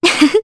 Scarlet-Vox_Happy1_Jp.wav